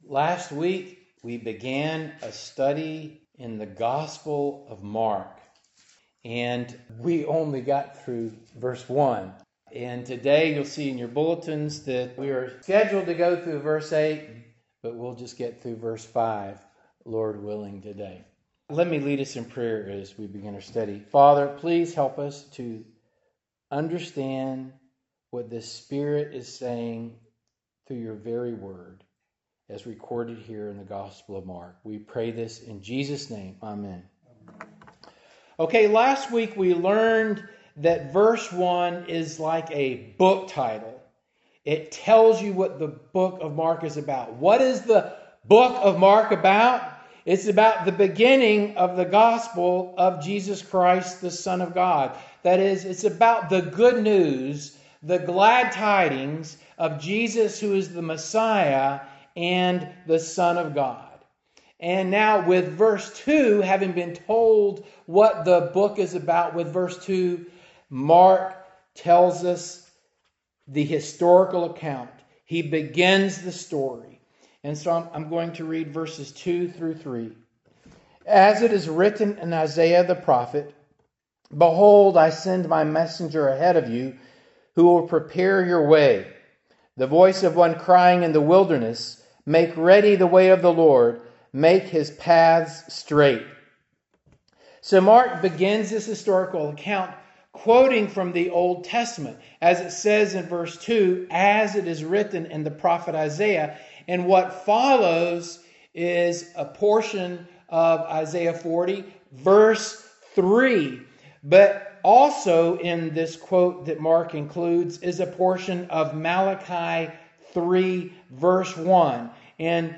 Passage: Mark 1:2-5 Service Type: Morning Service